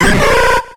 Cri d'Embrylex dans Pokémon X et Y.